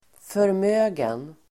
Uttal: [förm'ö:gen]